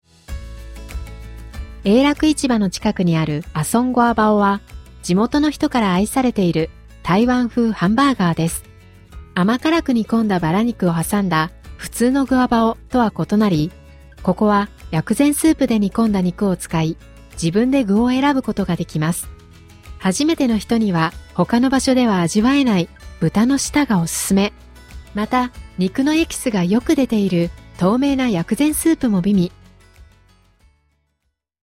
日本語音声ガイド